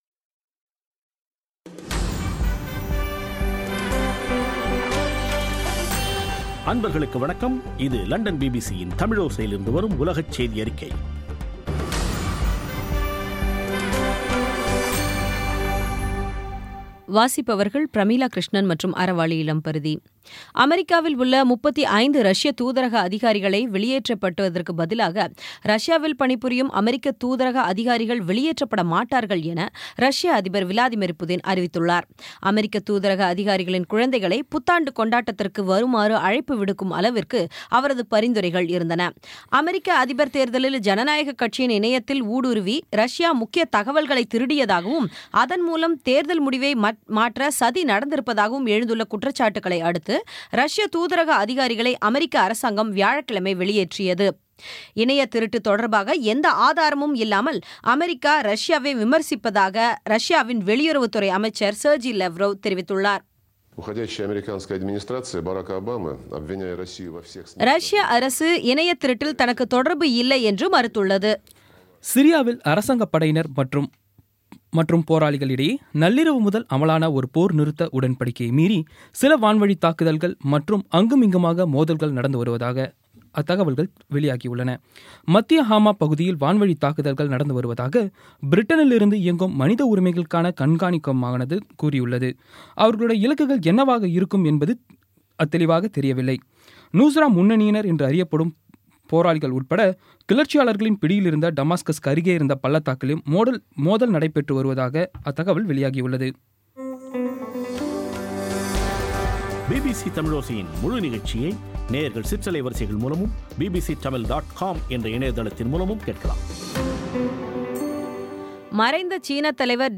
பி பி சி தமிழோசை செய்தியறிக்கை (30/12/2016)